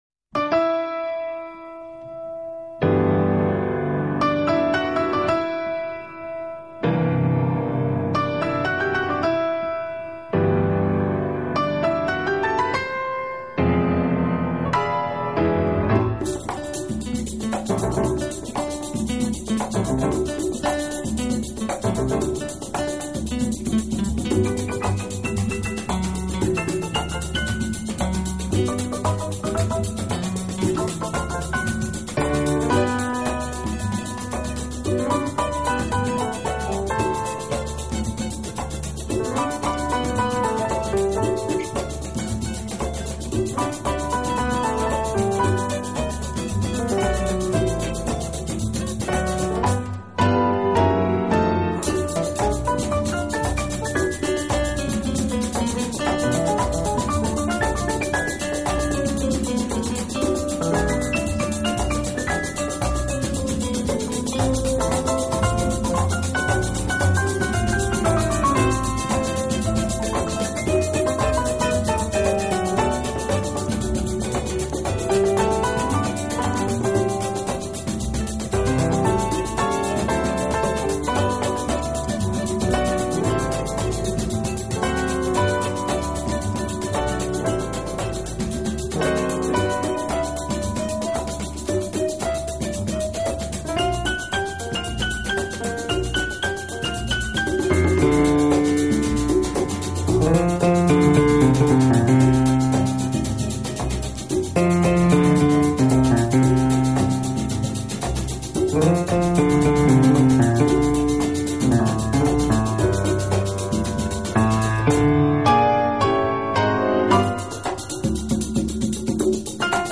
【轻音乐专辑】
演奏以轻音乐和舞曲为主。
已是83岁高龄，但他的音乐仍然洋溢着青春的律动。